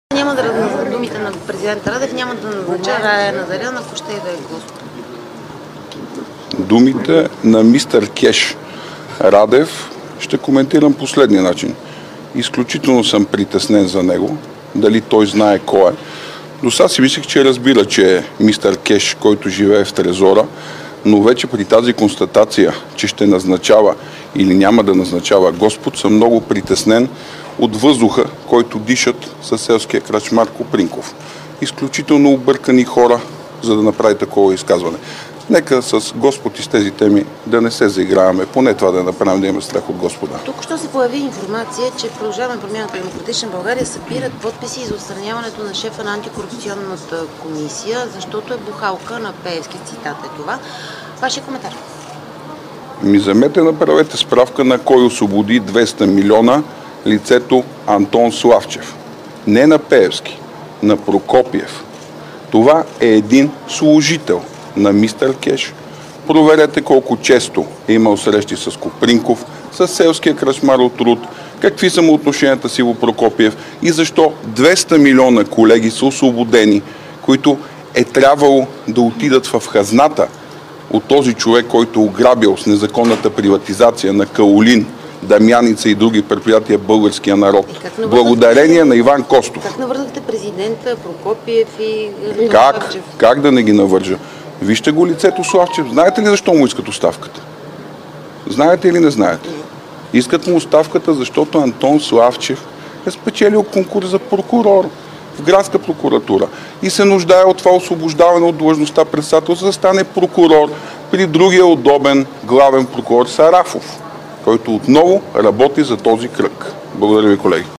9.20 - Брифинг на председателя на ГЕРБ Бойко Борисов за консултациите за служебен премиер и изборите. - директно от мястото на събитието (пл. „Княз Александър I" №1)
Директно от мястото на събитието